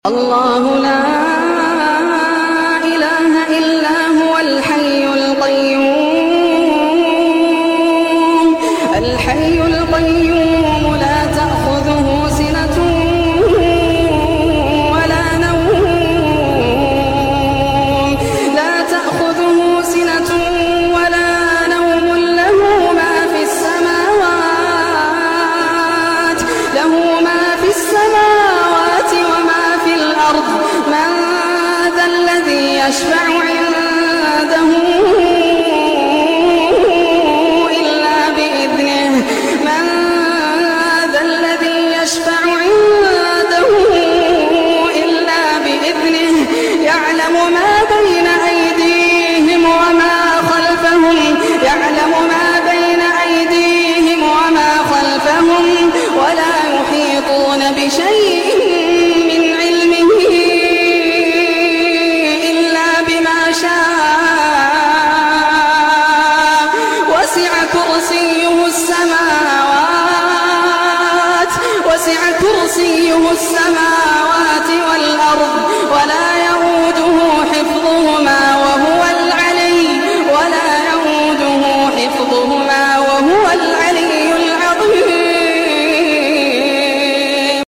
أعظم آية في Mp3 Sound Effect آية الكرسي… أعظم آية في كتاب الله، حرزٌ من الشرور، وأمانٌ من الخوف، وطمأنينةٌ تسكن القلوب. استمع إليها بترتيل خاشع يملأ روحك نورًا، وتعرّف على فضلها العظيم الذي أخبر به النبي ﷺ."